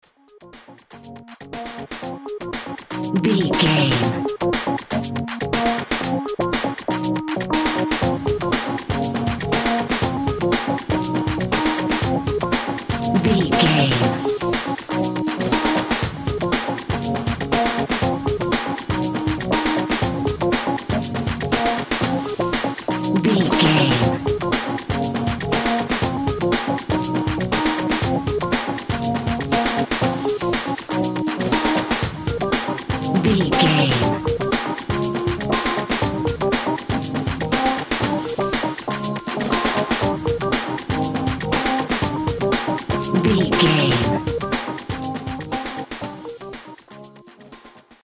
Aeolian/Minor
Drum and bass
break beat
electronic
sub bass
synth